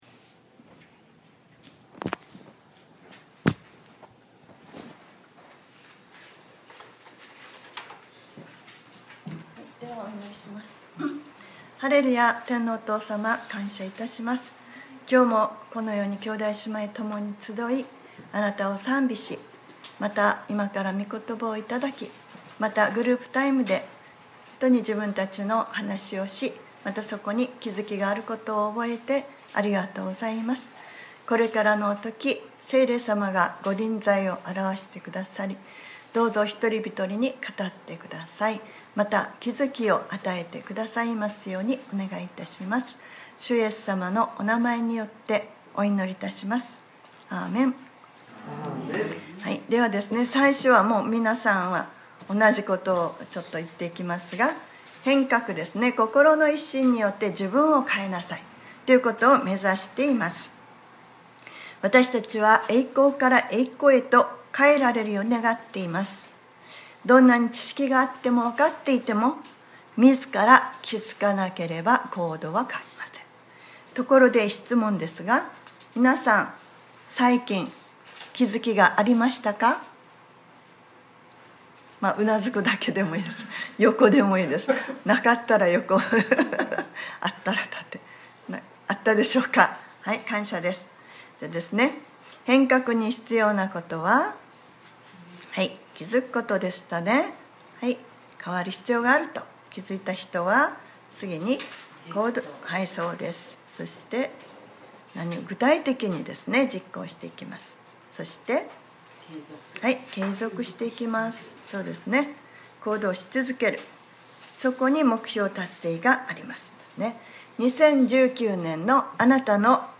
2019年05月19日（日）礼拝説教『ヴィジョン３』